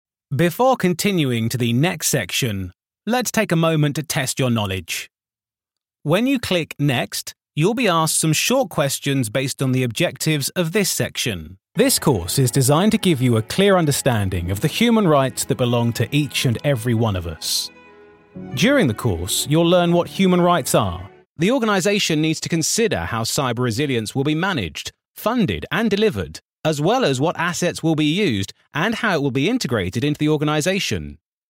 Inglés (Británico)
Comercial, Natural, Amable, Cálida, Empresarial
E-learning